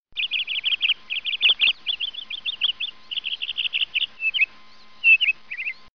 canary-trills.mp3